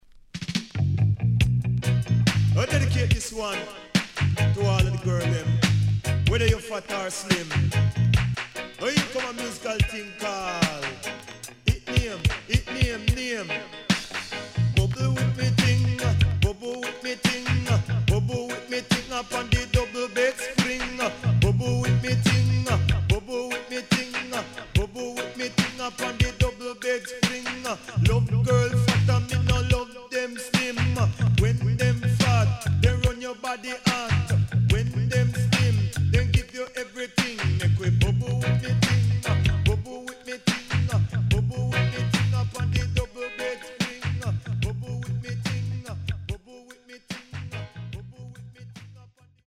HOME > LP [DANCEHALL]